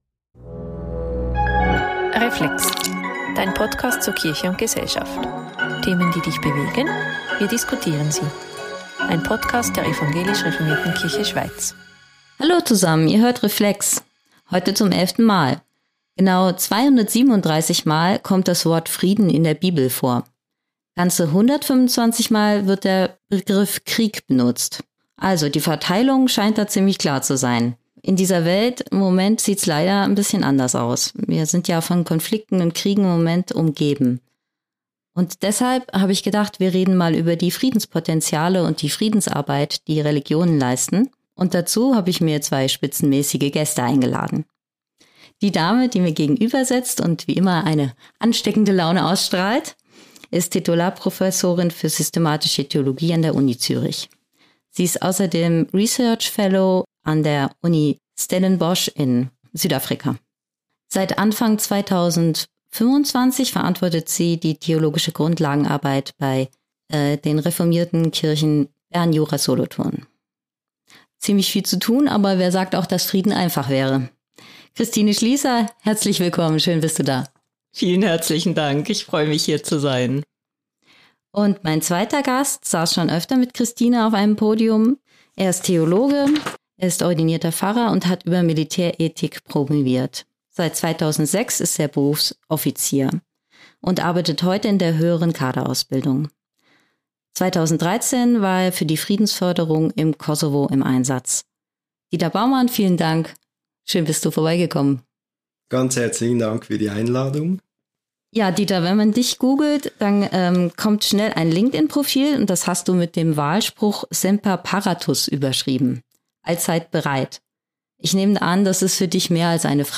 Beschreibung vor 7 Monaten Frieden schaffen und erhalten: Wie soll man das inmitten von Kriegen und Krisen anstellen? Hier sind auch Religionen wichtige Ressourcen, die in Friedens- und Versöhnungsprozessen mithelfen. Über Krieg, Frieden und alles dazwischen diskutieren in dieser Ausgabe von Reflex